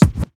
FX (Scratch).wav